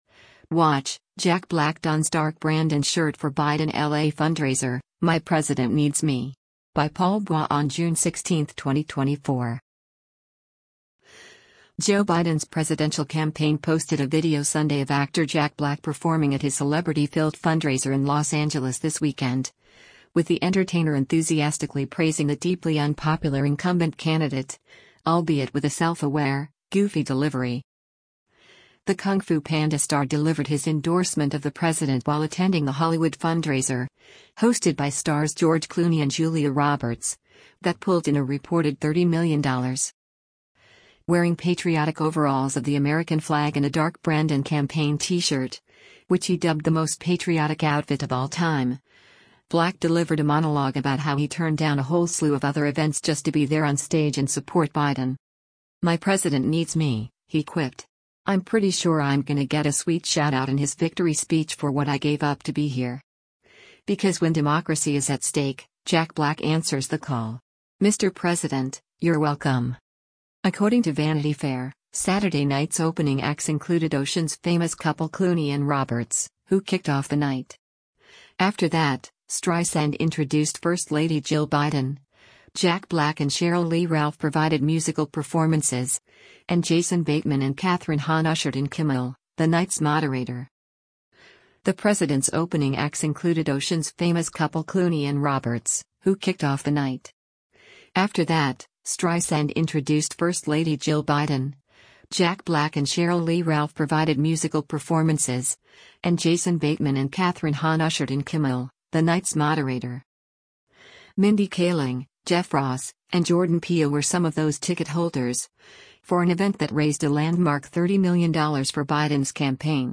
Joe Biden’s presidential campaign posted a video Sunday of actor Jack Black performing at his celebrity-filled fundraiser in Los Angeles this weekend, with the entertainer enthusiastically praising the deeply unpopular incumbent candidate — albeit with a self-aware, goofy delivery.
Wearing patriotic overalls of the American flag and a “Dark Brandon” campaign T-shirt (which he dubbed “the most patriotic outfit of all time”), Black delivered a monologue about how he turned down a whole slew of other events just to be there on stage and support Biden.